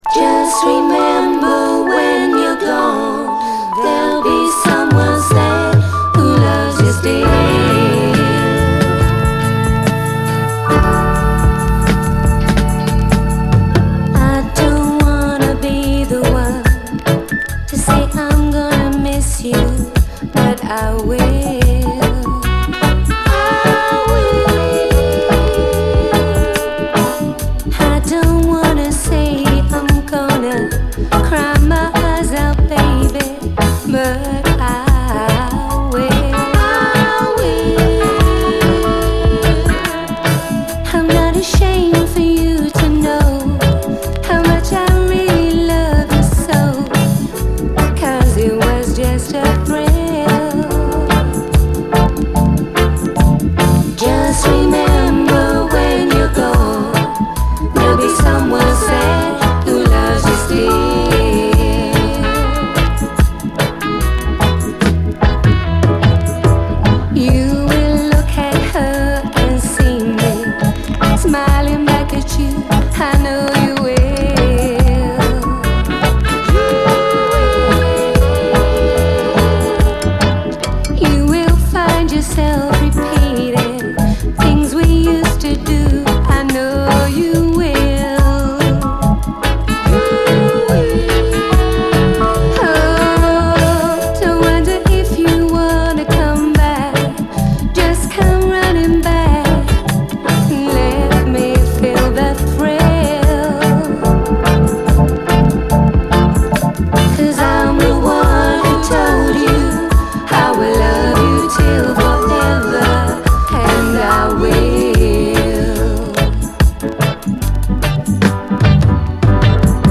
REGGAE, 7INCH
77年というUKラヴァーズ初期の作品にして、歌声からサウンドまでが完璧です！ダブ・ヴァージョンも収録！